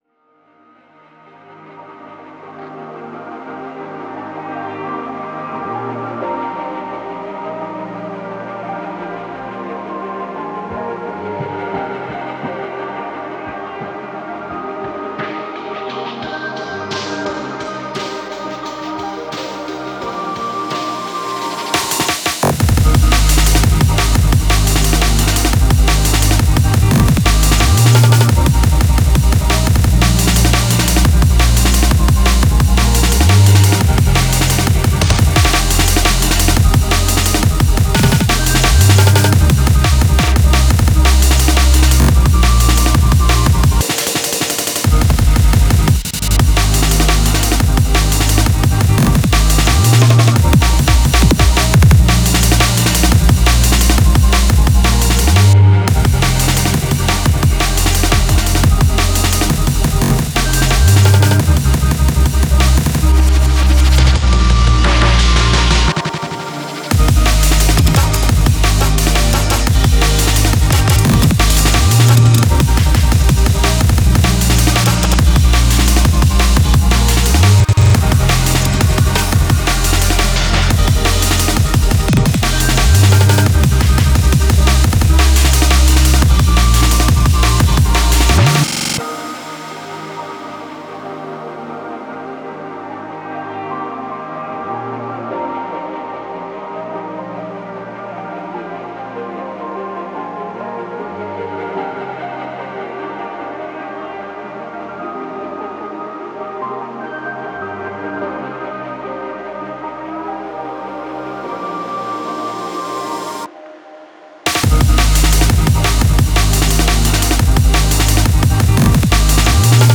a more feely track